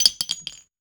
weapon_ammo_drop_14.wav